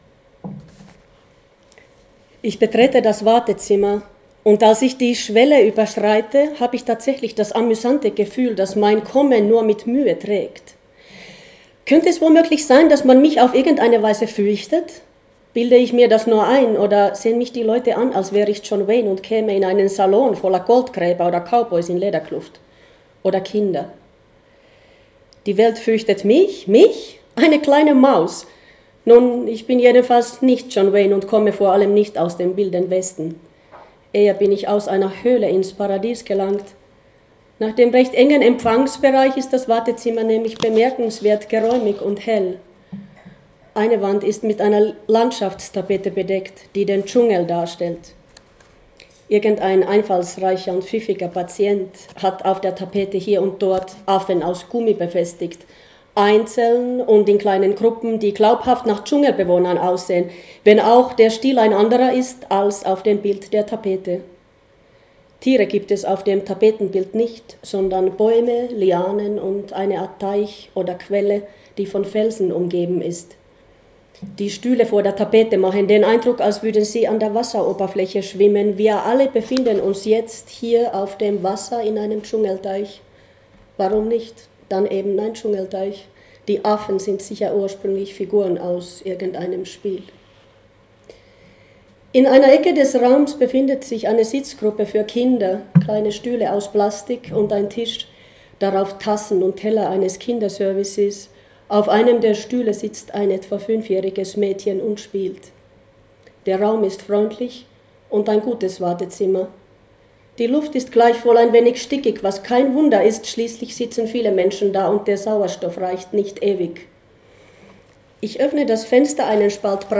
KINDER, Lesung in Salzburg, bei der Leselampe, 5.3.2019, von zwei Stellen vom Roman. Es geht um Wartezimmer, Lavendel, Kinder und Erwachsene (ab Seite 100). Und um U-Bahn, Tomaten, Elefanten, Angst und Glück (ab Seite 230).